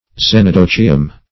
xenodochium - definition of xenodochium - synonyms, pronunciation, spelling from Free Dictionary
Xenodochium \Xen`o*do*chi"um\ (z[e^]n`[-o]*d[-o]*k[imac]"[u^]m),